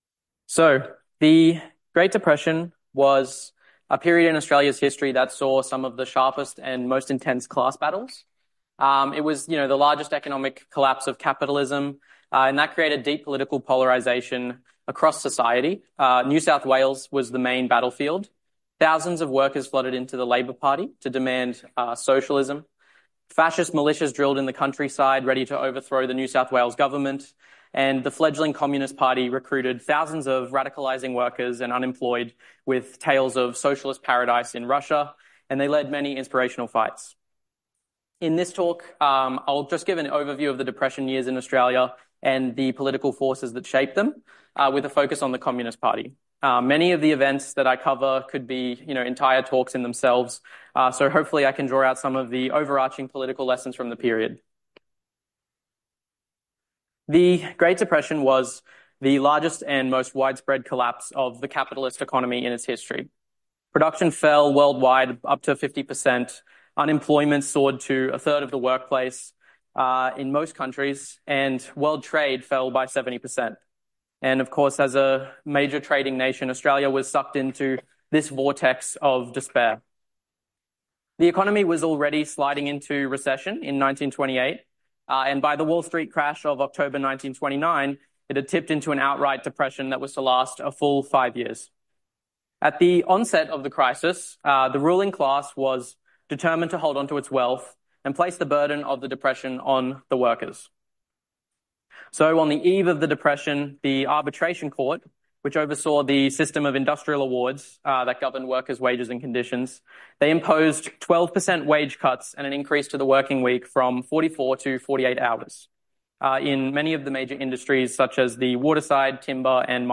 Socialism 2025 (Brisbane)